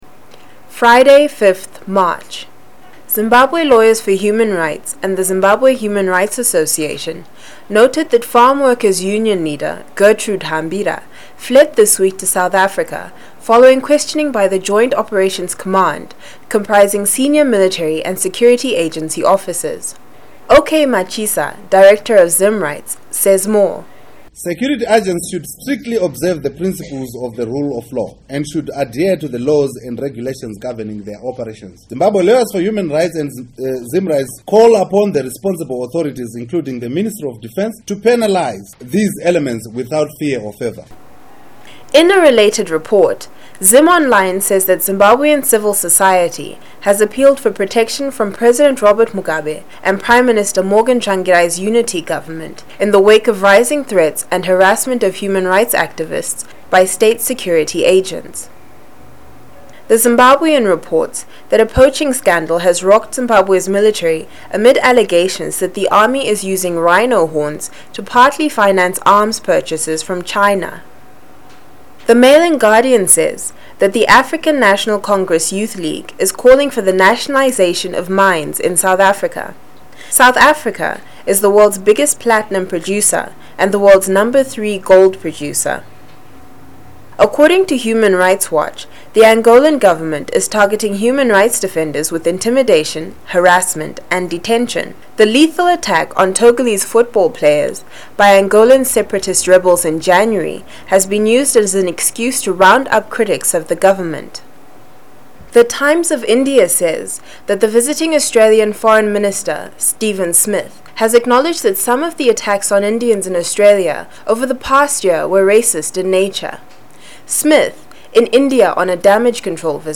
News headlines     shares a round up of local, regional and international news daily